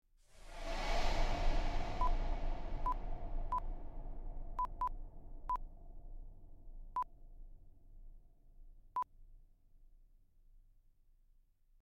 Long Ghostly Whoosh 2 (sound FX)
Ghostly Whoosh. Air burst. Monster breathe. Ship Passing. Multimedia Sound Effects, Whooshes
Long_Ghostly_Whoosh_2_plip.mp3